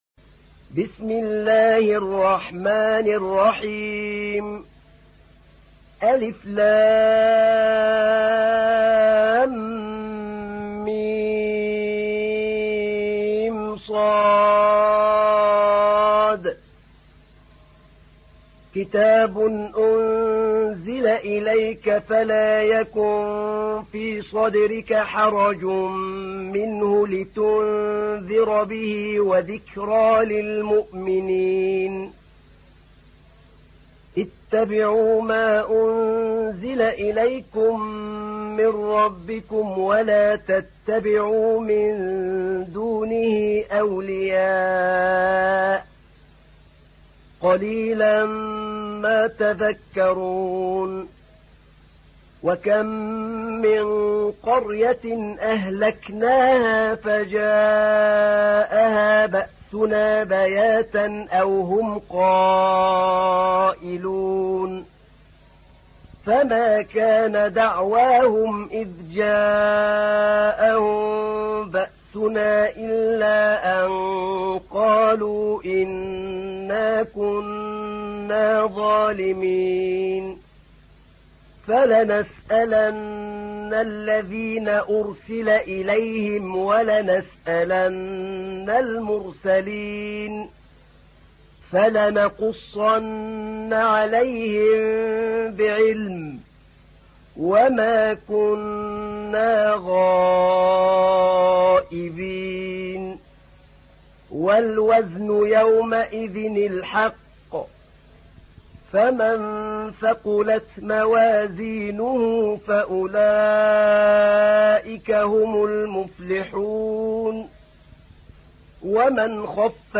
تحميل : 7. سورة الأعراف / القارئ أحمد نعينع / القرآن الكريم / موقع يا حسين